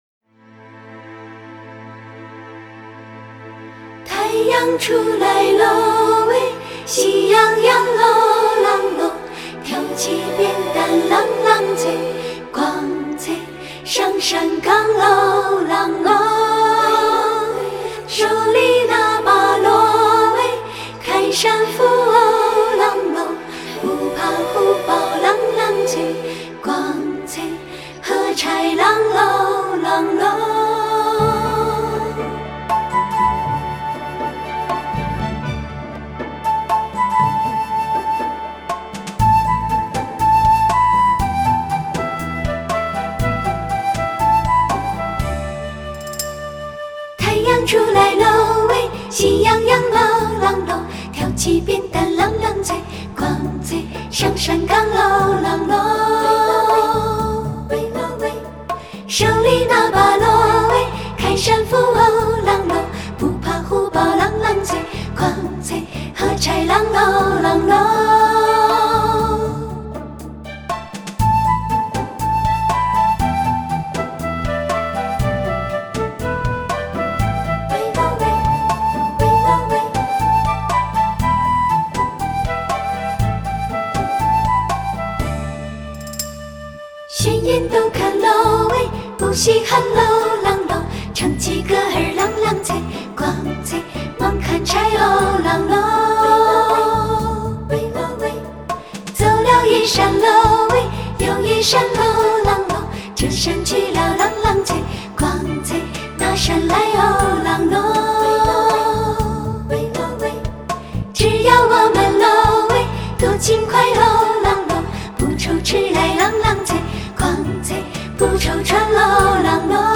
她们的声音表情凌于技巧之上，以情带声，情声造境，快歌轻盈如溪，慢歌绵长有致。